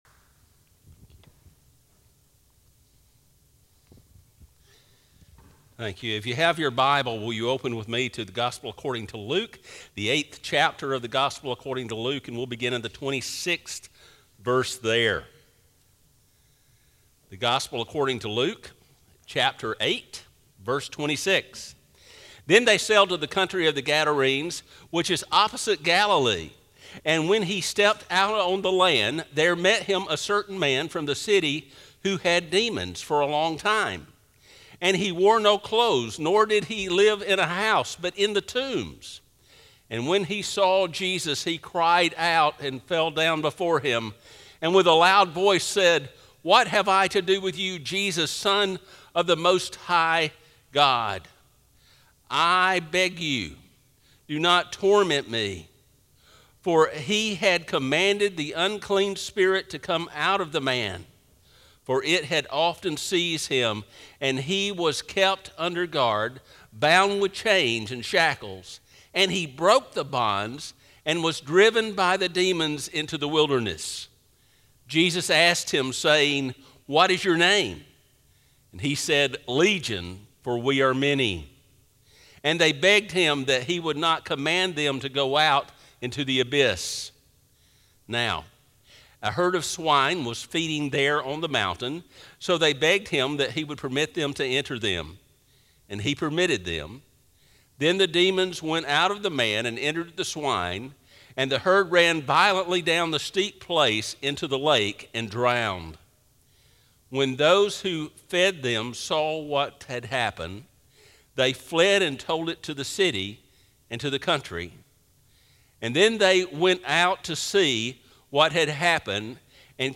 Sermons | Northside Baptist Church